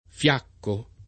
fiaccare
fiacco [ f L# kko ], ‑chi